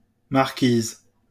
A woman with the rank of a marquess or the wife (or widow) of a marquess is a marchioness (/mɑːrˈʃənɛs/[3]) or marquise (French: [maʁkiz]